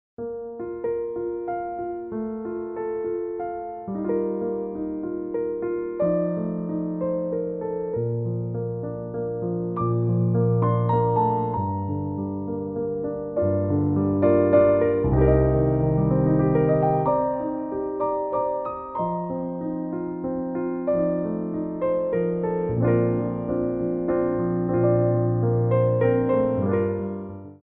Port de Bras / Révérance
3/4 (8x8) + (2x8)